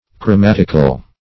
Chromatical \Chro*mat"ic*al\, a.
chromatical.mp3